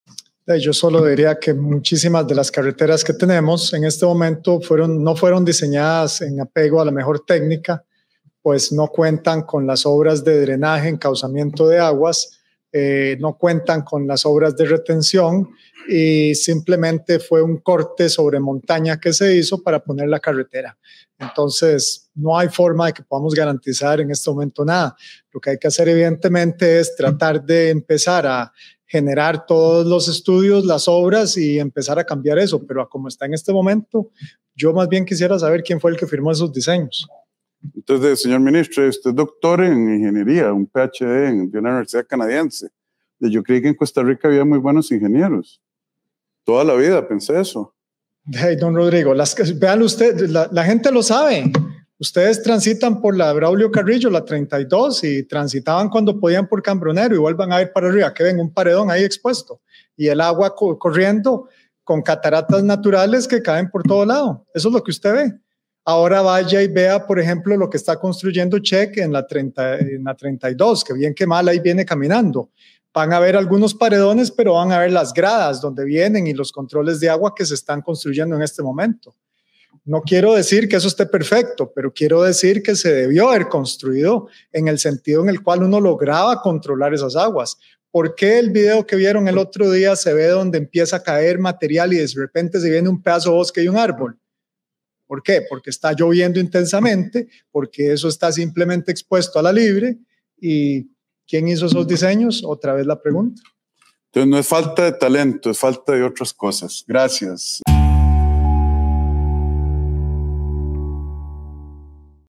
En declaraciones dadas durante la conferencia de prensa del Consejo de Gobierno Amador afirmó que “muchísimas de las carreteras que tenemos en estos momentos no fueron diseñadas, en apego a la mejor técnica”.